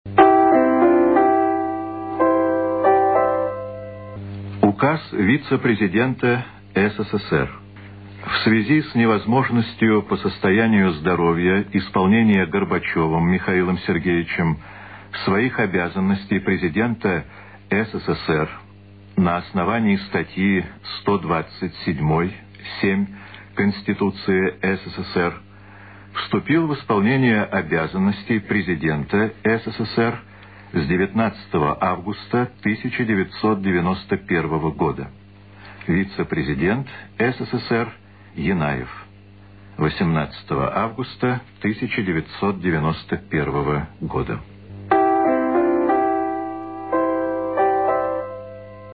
Радыё Свабода У выпуску навінаў 19 жніўня 1991 году па радыё голасам дыктара Ігара Кірылава было абвешчана, што ў краіне ўводзіцца надзвычайнае становішча.
Гучыць фрагмэнт Указу ГКЧП.